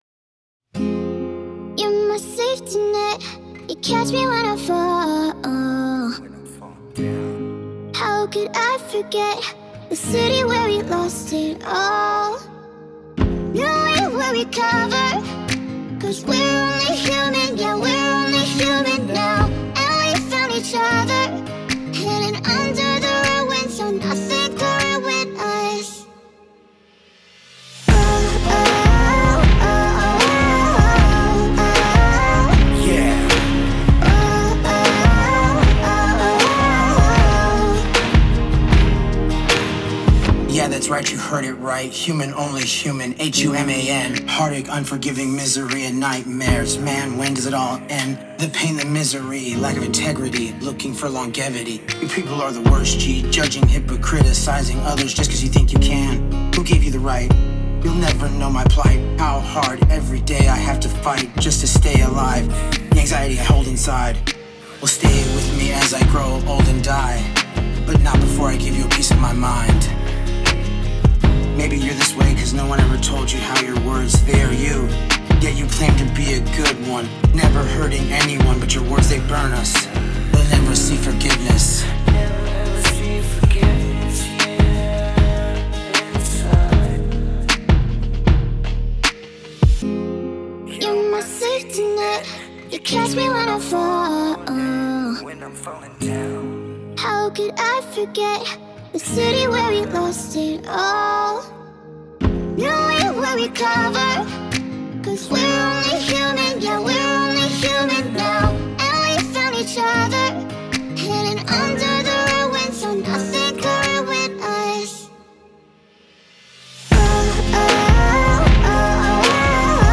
Date: 2026-01-04 · Mood: dark · Tempo: 62 BPM · Key: D major